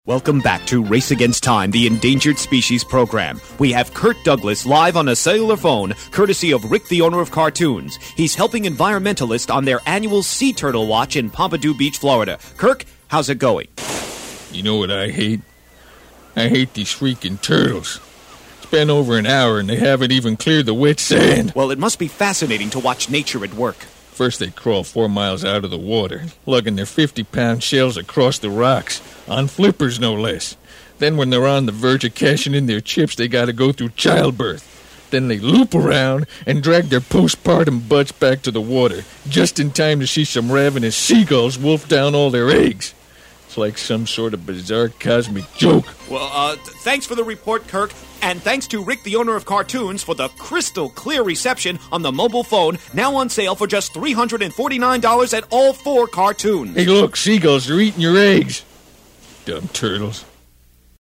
Side B: Commercials & Stuff